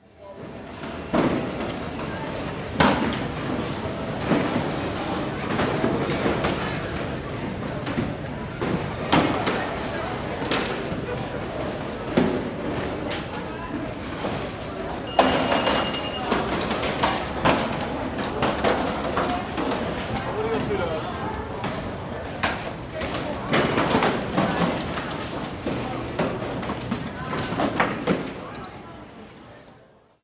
Звуки аэропорта, атмосфера
Проверка багажа и контроль